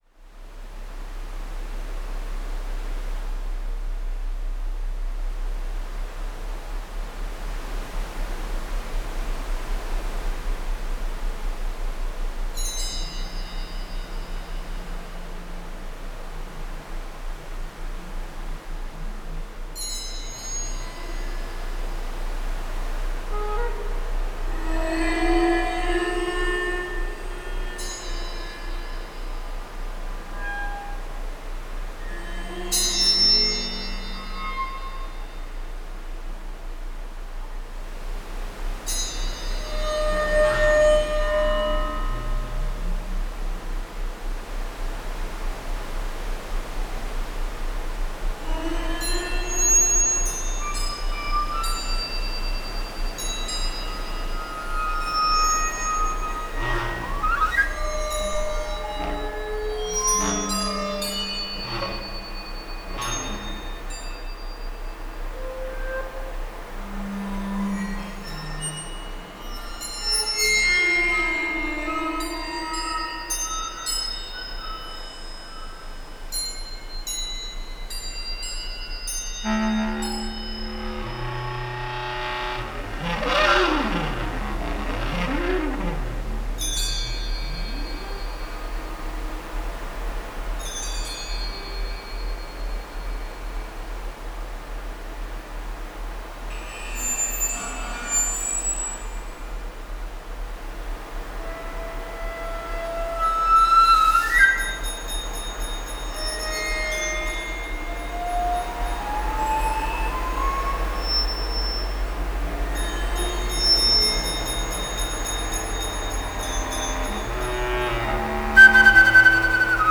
Adding samples to those waves
I’ve composed the rest of the sound for the sick bay today, using more metallic samples for the industrial sounds.